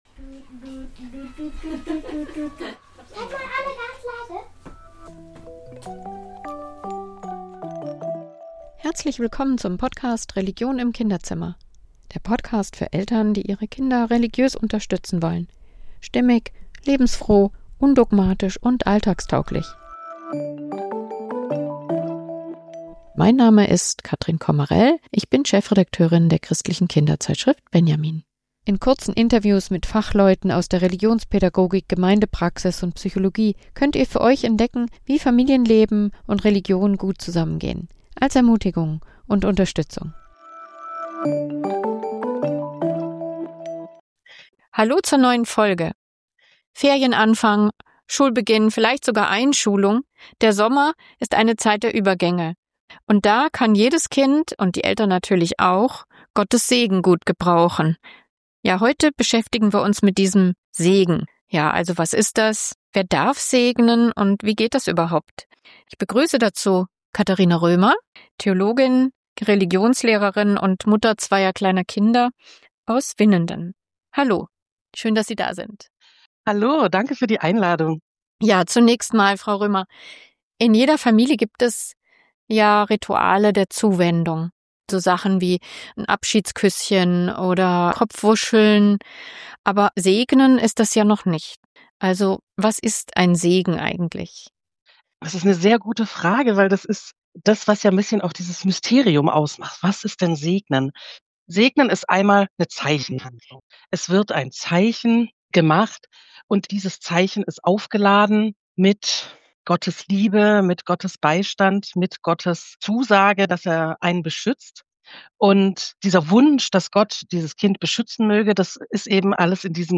Im Gespräch geht es auch darum, wie solche Rituale Kinder stärken, Eltern entlasten und gerade in Übergangszeiten – wie Schulanfang oder Ferienstart – ein Gefühl der Geborgenheit schaffen können.